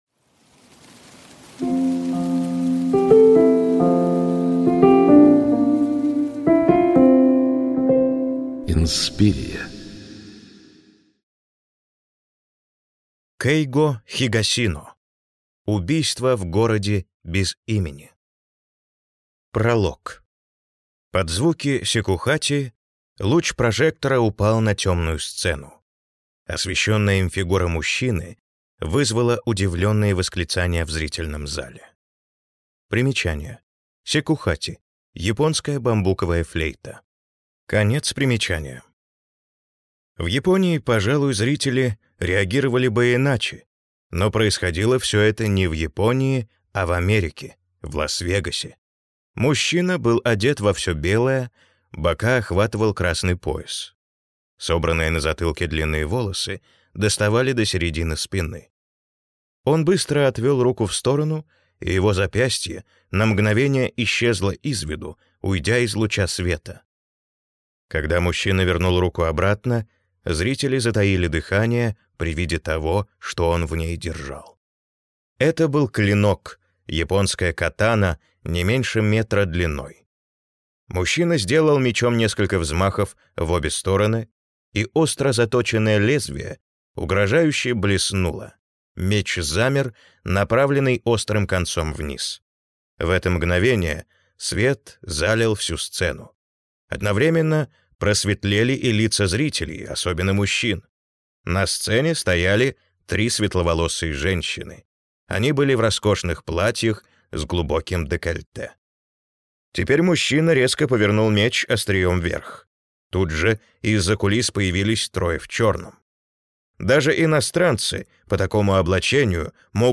Аудиокнига Убийство в городе без имени | Библиотека аудиокниг